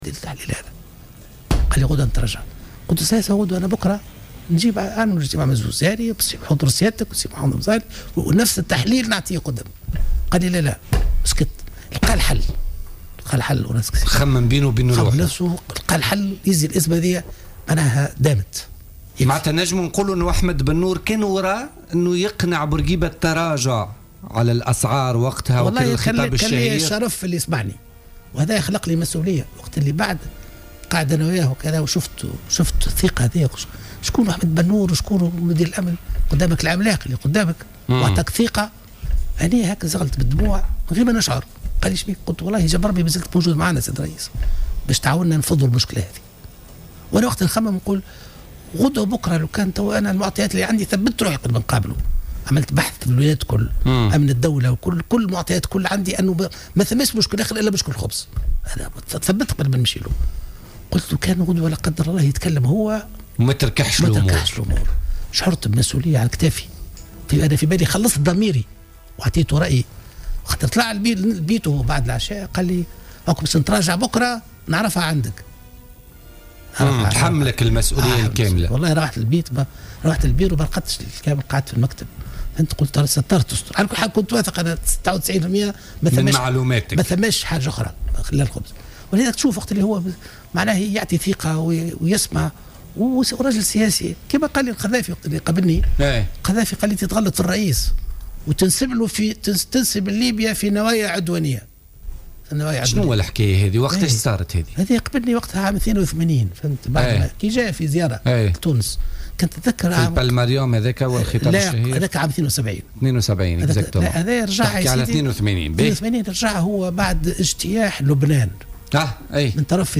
قال أحمد بنّور مدير الأمن والمخابرات وكاتب الدولة التونسي الأسبق للدفاع في عهد الرئيس الحبيب بورقيبة ضيف بوليتيكا اليوم الخميس 24 مارس 2016 إن معمر القذافي خطط سنة 1982 لتفجير نزل الهيلتون بتونس الذي احتضن آنذاك اجتماعا طارئا لوزراء الخارجية العرب على خلفية اجتياح لبنان من طرف اسرائيل.